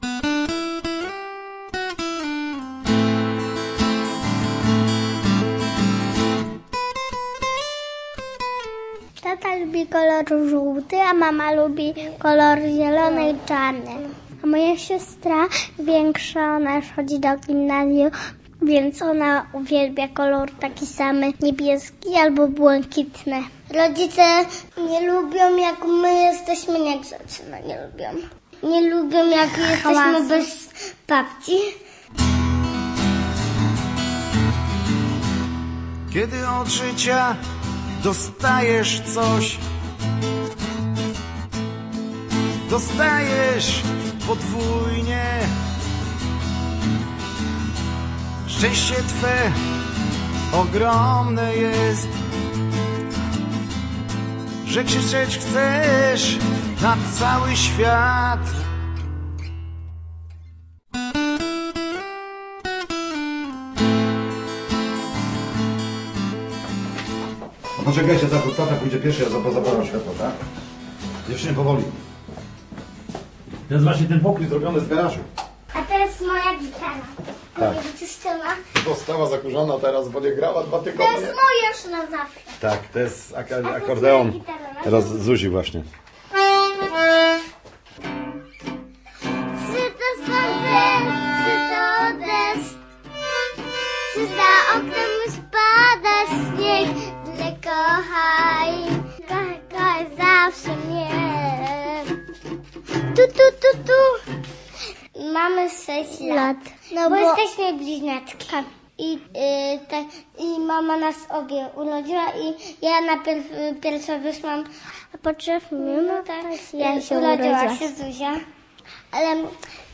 Chciałem być jak ojciec - reportaż